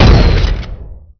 nail_fire.wav